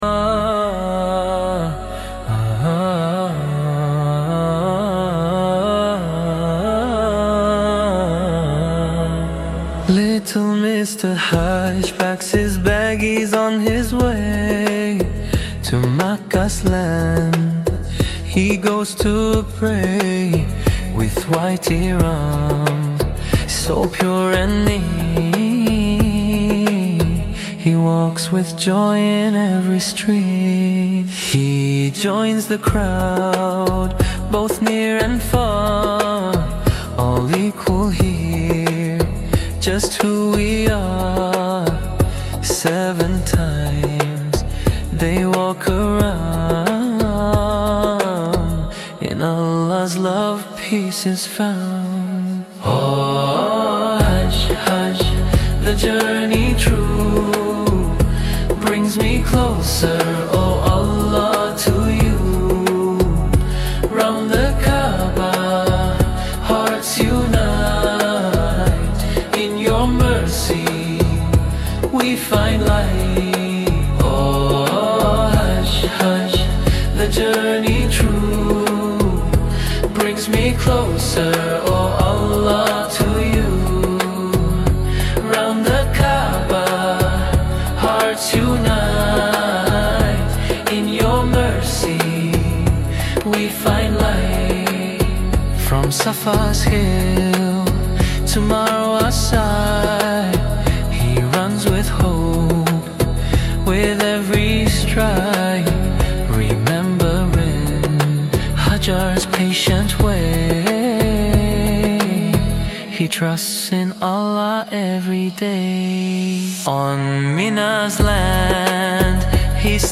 Fun Islamic song for kids